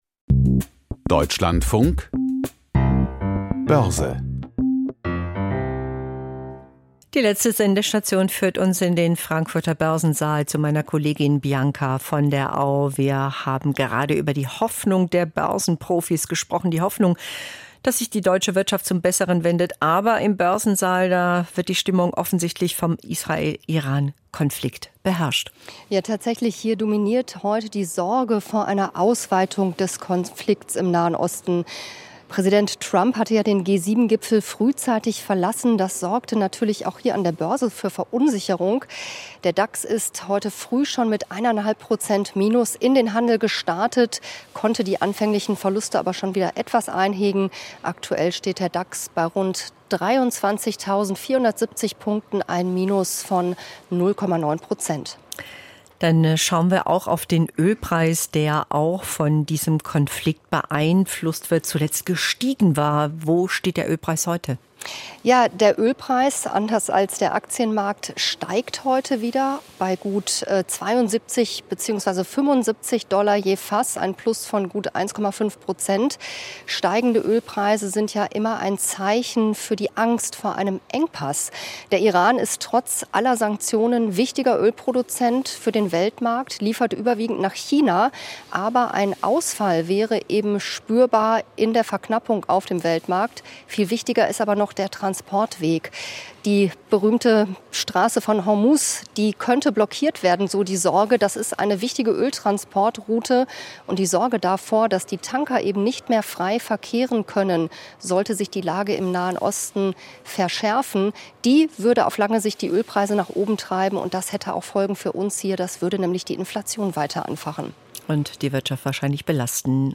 Börsenbericht aus Frankfurt